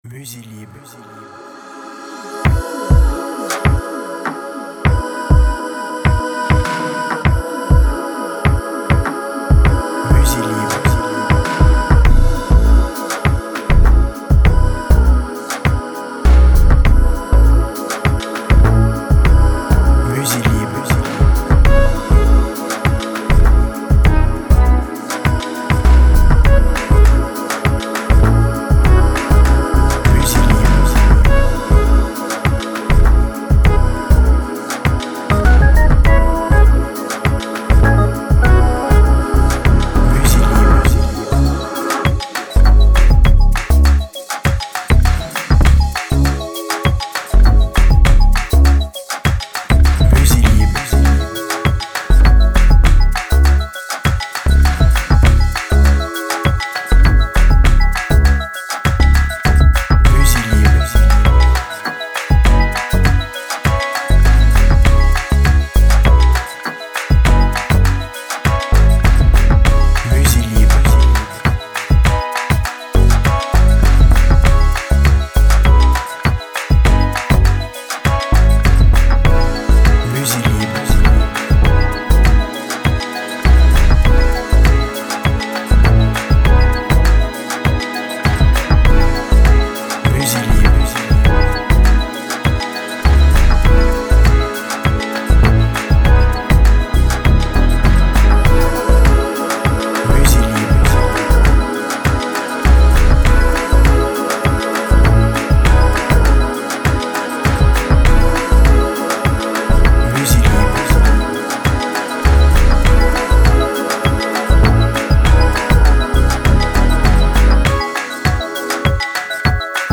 BPM Moyen